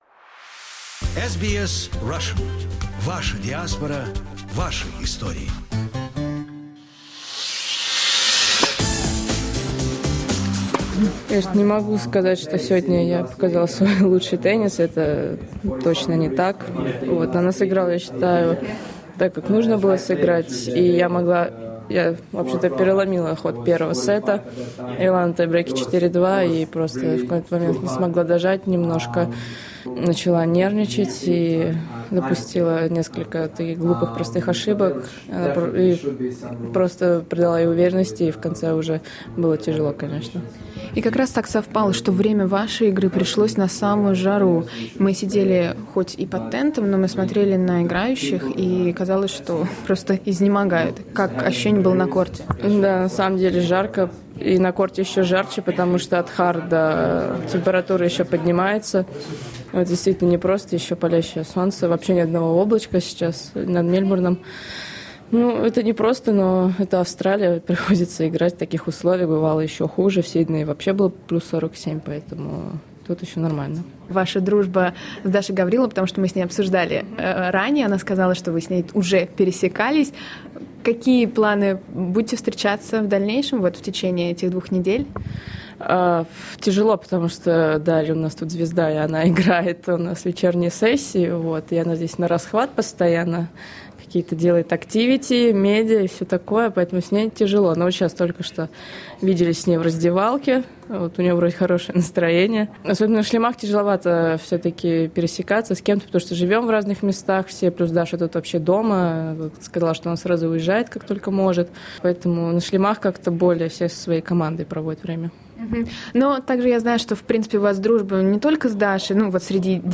We spoke with Daria after she lost to Polish Magda Linette 7(7)-6(4), 6-2 which was a very sad loss for her.